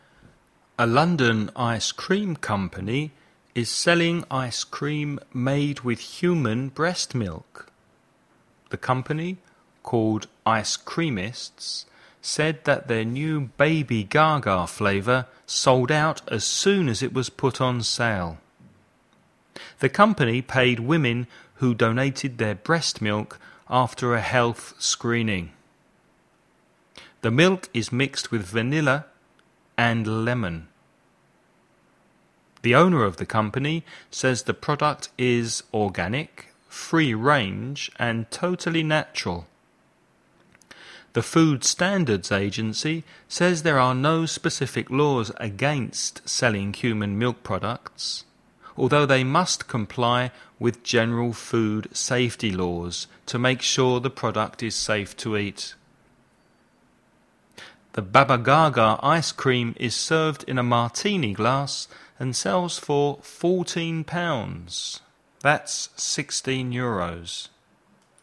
DICTATION / DICTADO
1. Listen to the text read at normal speed. (Primero escucha el dictado a una velocidad normal)